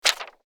paper_pickup3.wav